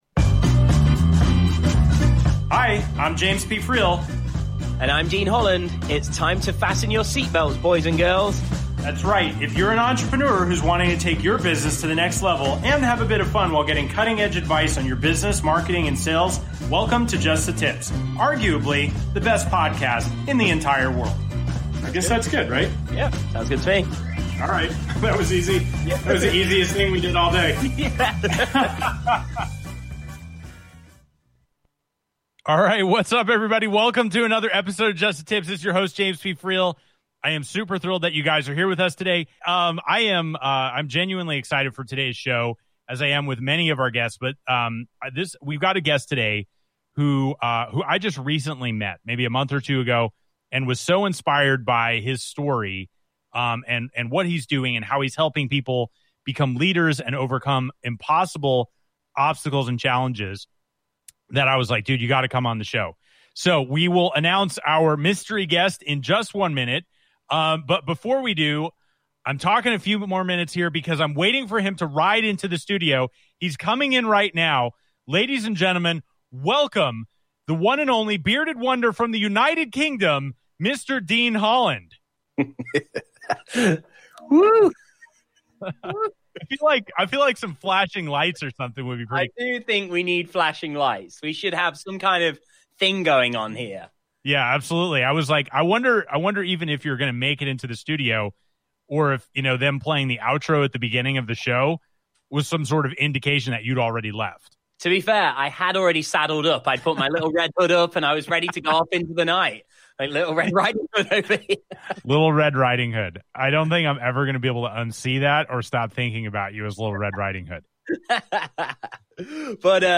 Just The Tips is a business talk show for entrepreneurs and business owners who are tired of listening to the same old stodgy content. Interviewing (and sometimes roasting) top entrepreneurs from around the world, each episode is fun, witty and informative.